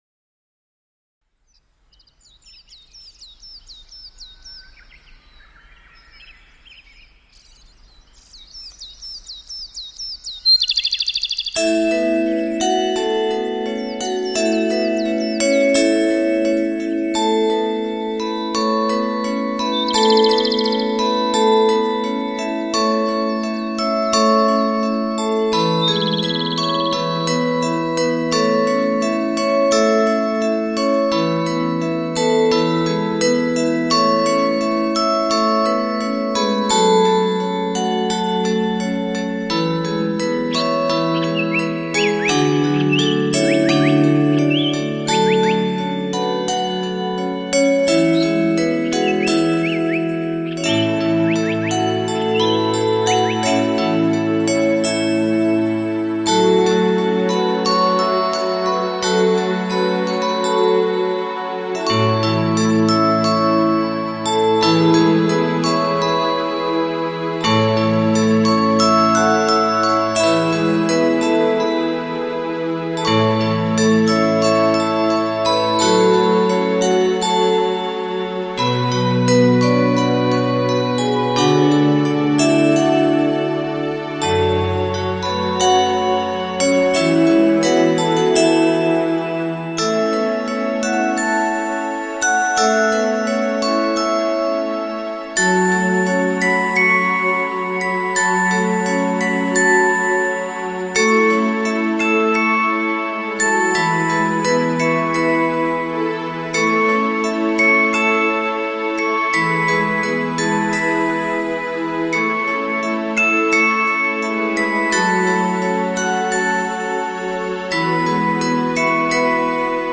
轻轻地闭上眼睛，放鬆筋骨神经，沉浸在水晶的音符裡涤盪、漂淨……